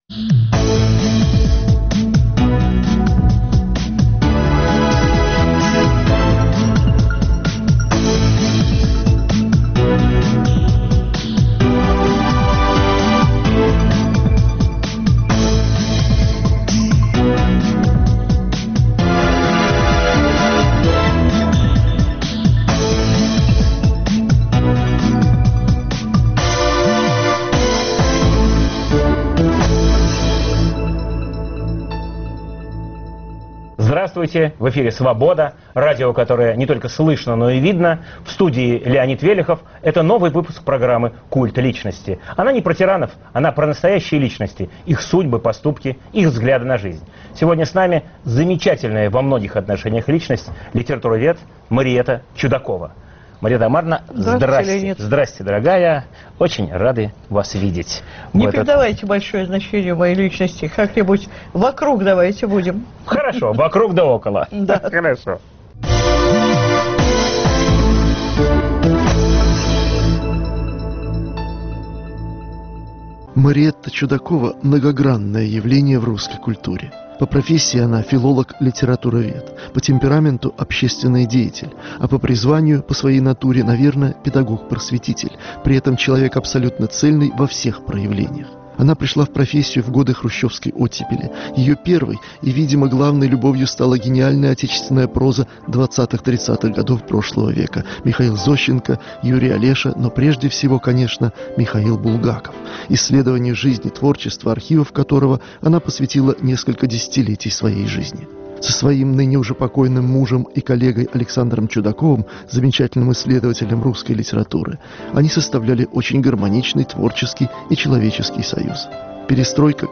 Новый выпуск программы о настоящих личностях, их судьбах, поступках и взглядах на жизнь. В студии "Культа личности" литературовед Мариэтта Чудакова. Ведущий - Леонид Велехов.